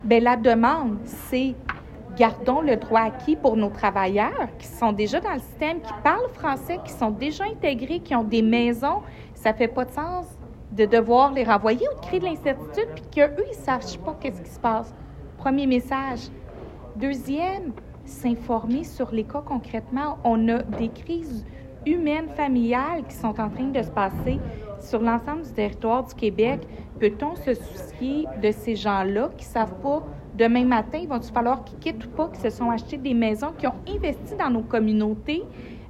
La mairesse Julie Bourdon.